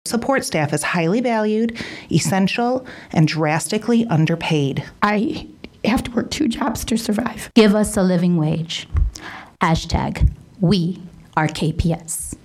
The board had the slogan thrown back at them by support personnel, including bus drivers, para-professionals, and other part-timers who say they can’t survive on what they are paid.